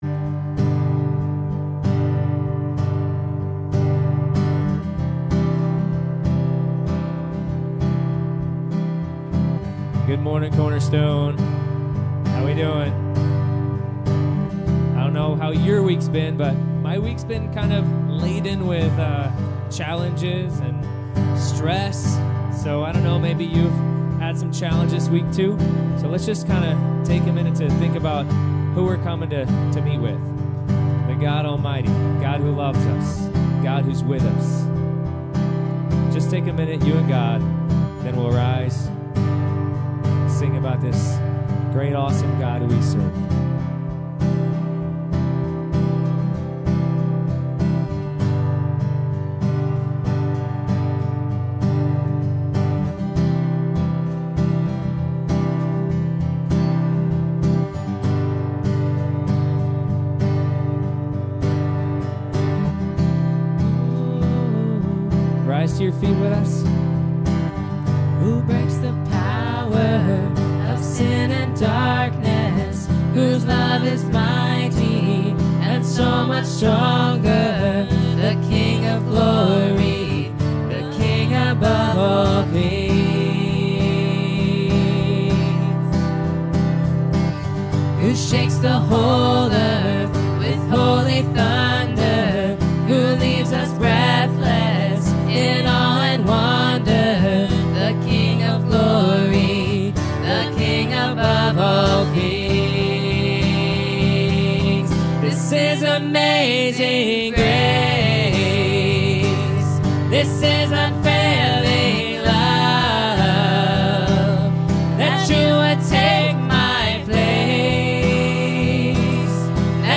Service Type: Sunday Morning